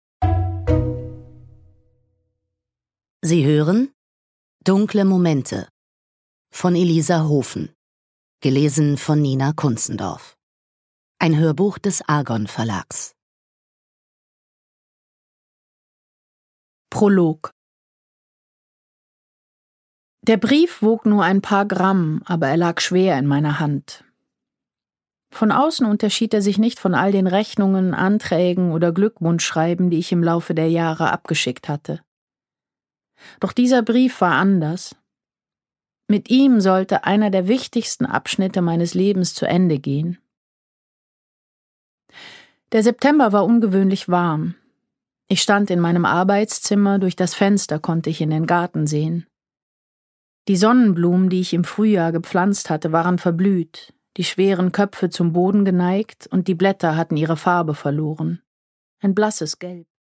Produkttyp: Hörbuch-Download
Gelesen von: Nina Kunzendorf